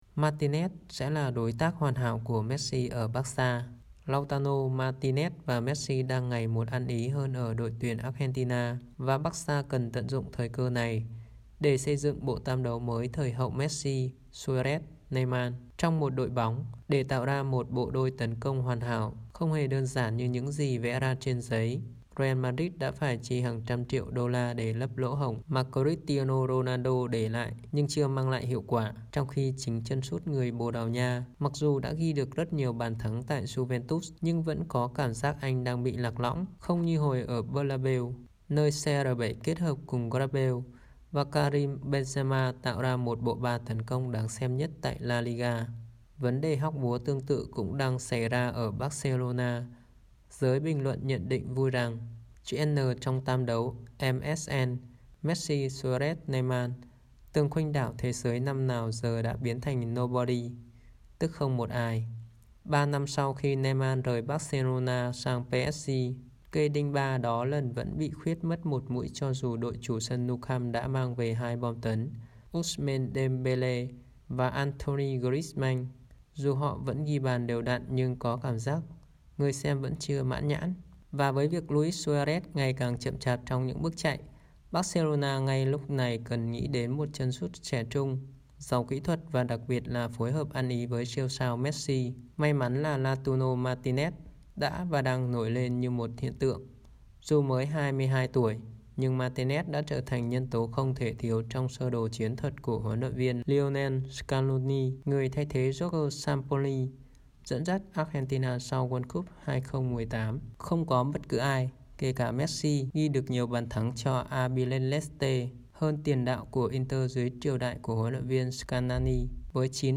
Bản tin audio